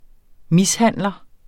Udtale [ ˈmisˌhanˀlʌ ]